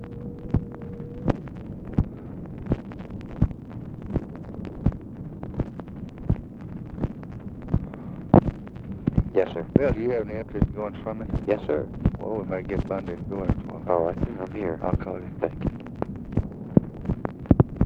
Conversation with BILL MOYERS, February 24, 1964
Secret White House Tapes